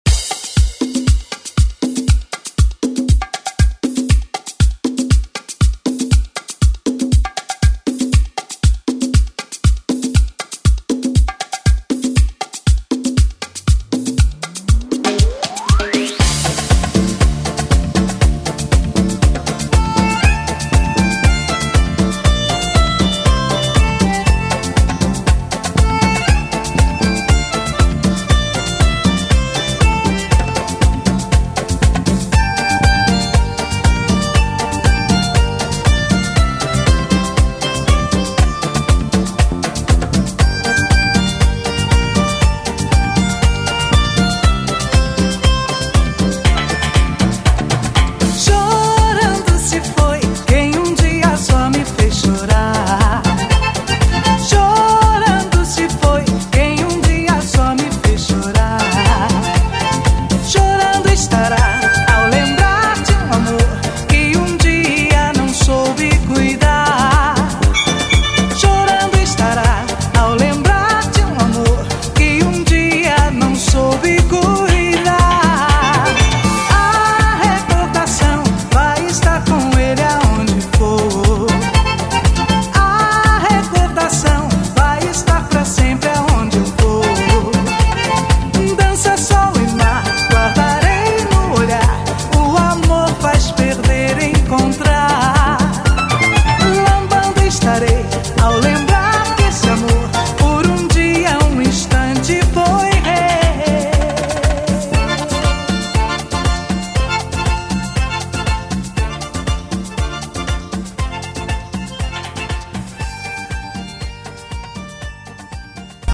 Genres: 80's , RE-DRUM , ROCK